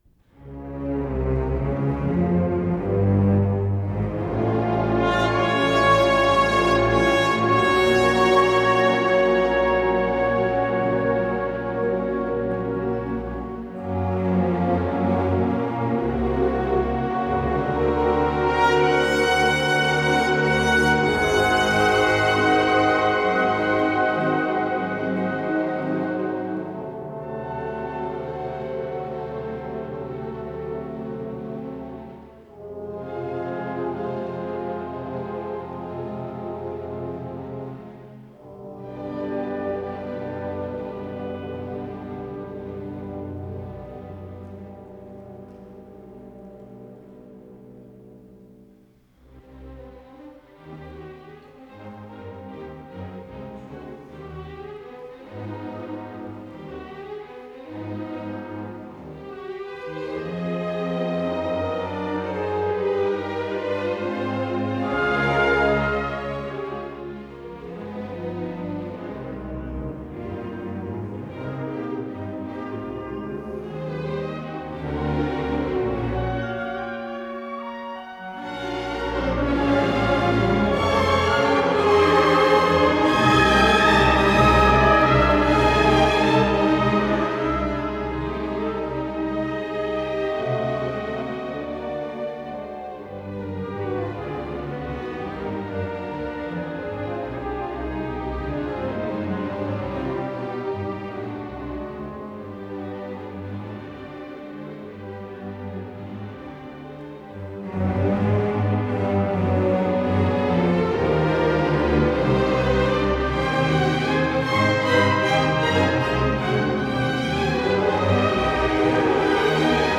Исполнитель: Большой симфонический оркестр Всесоюзного радио и Центрального телевидения
до минор